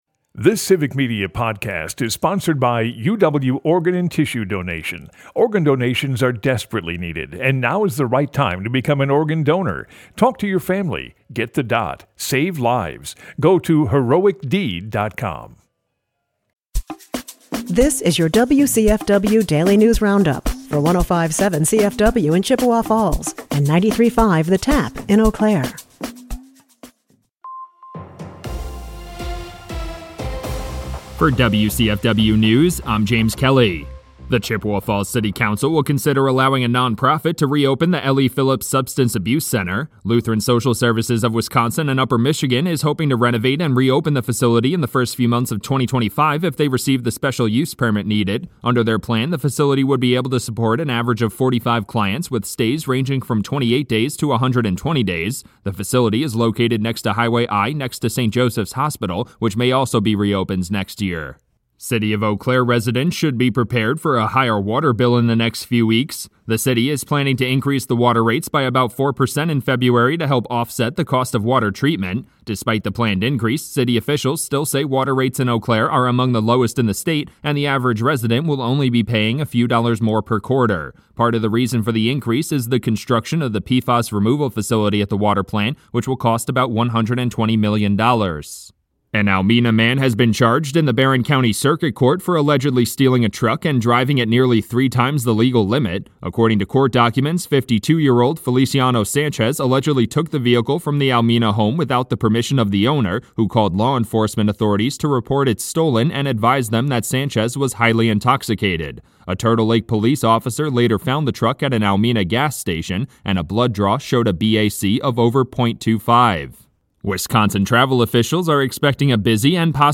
WCFW Tuesday News Roundup - WCFW News